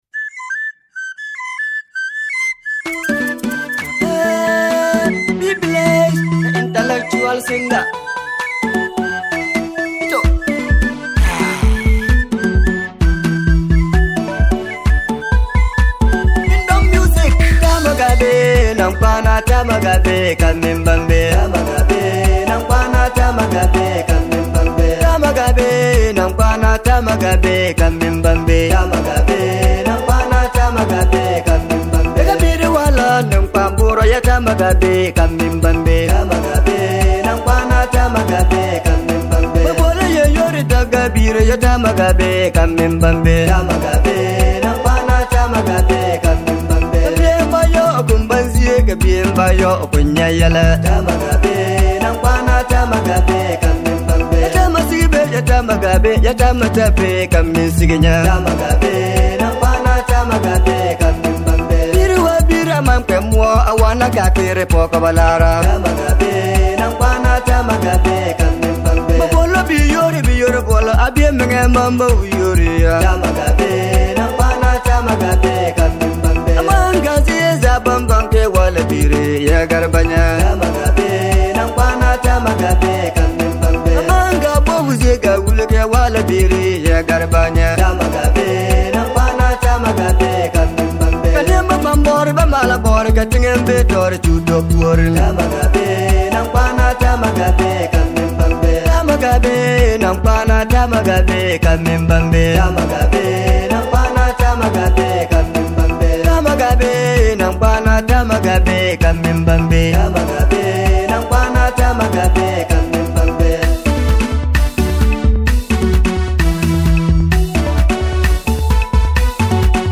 local and traditional song